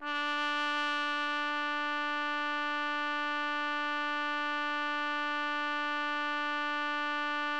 TRUMPET    9.wav